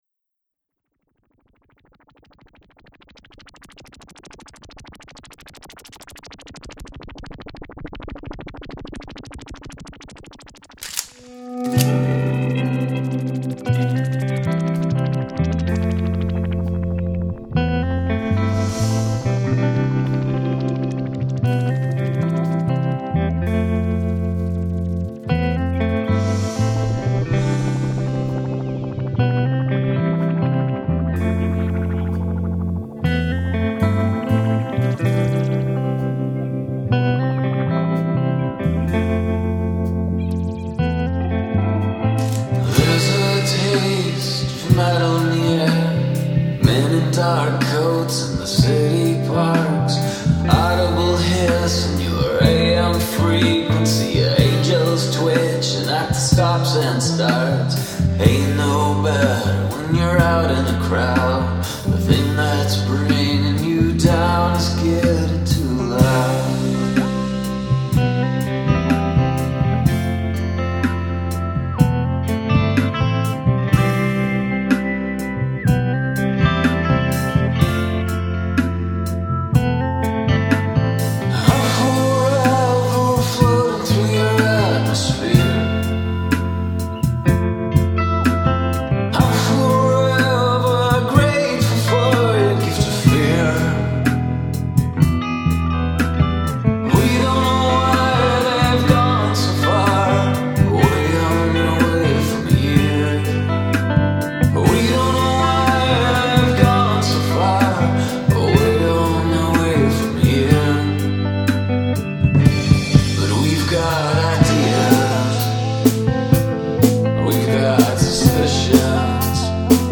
I played in a band called Tom’s Fury back in 2004 – 2005 that had a slightly heavy Americana feel to it.